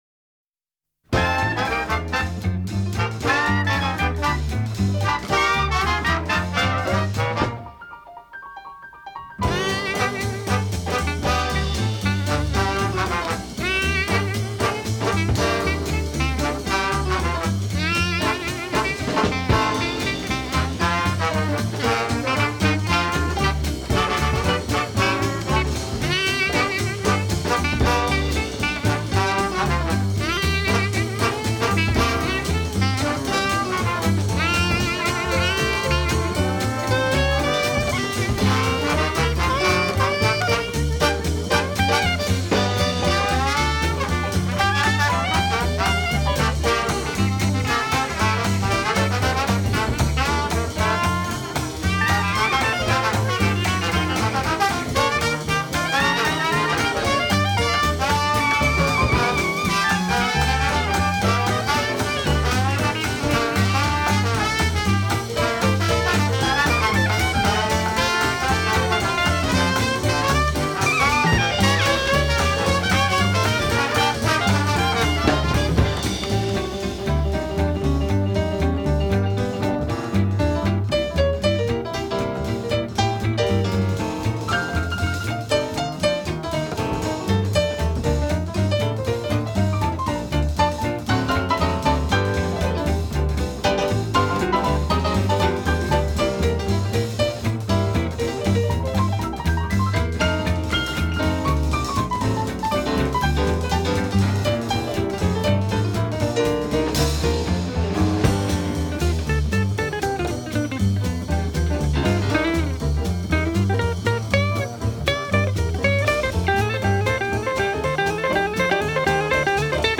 Веселые диксиленды послушаем .
диксиленд